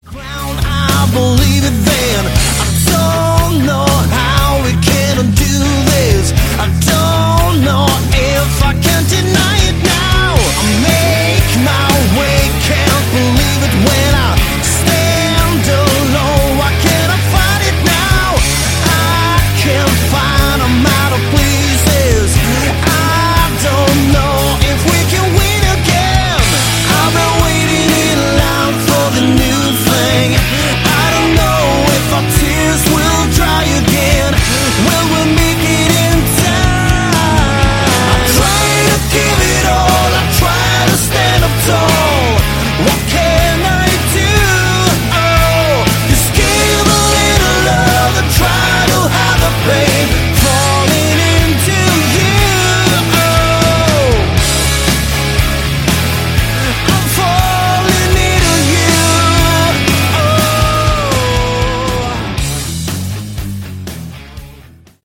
Category: Melodic Rock
Lead Vocals, Guitar
Drums, Percussion
Bass